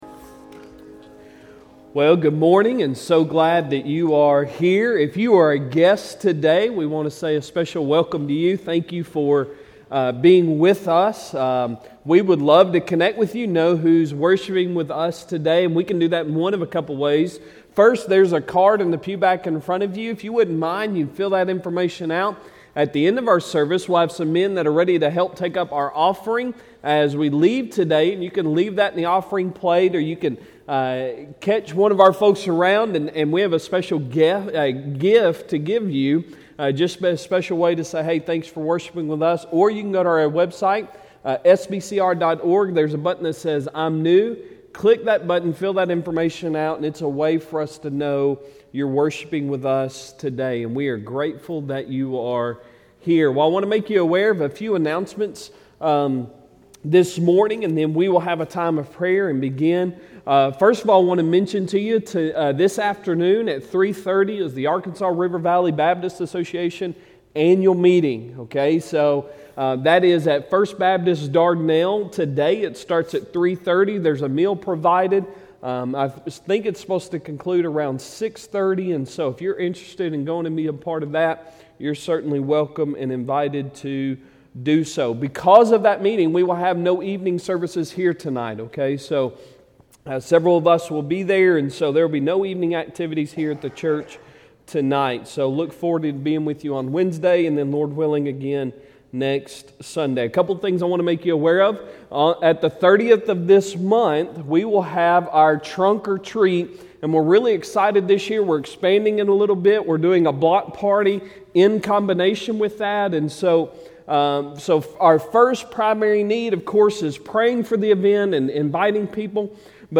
Sunday Sermon October 9, 2022